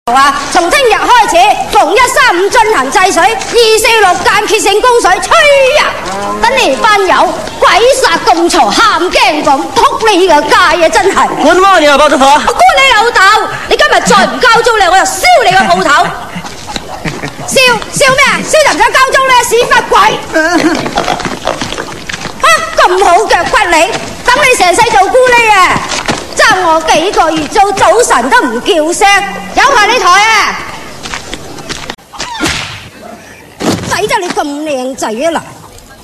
分类：搞笑铃声